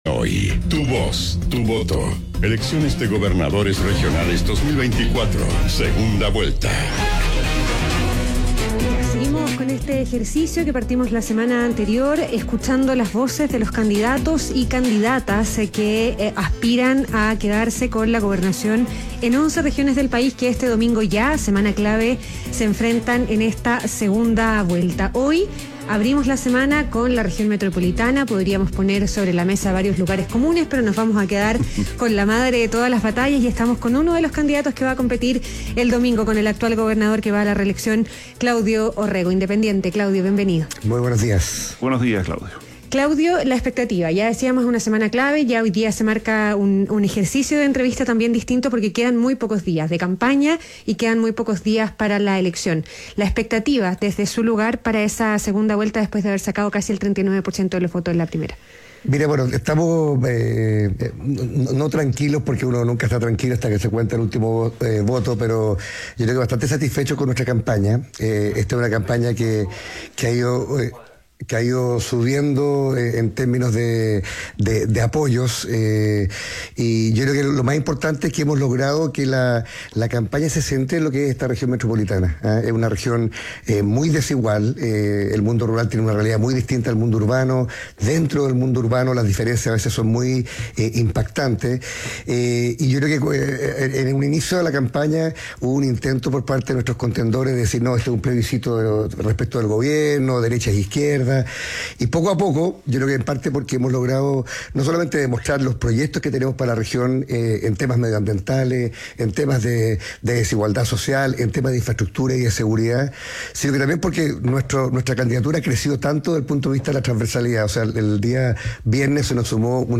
Entrevista a Claudio Orrego, gobernador y candidato a la reelección por la Región Metropolitana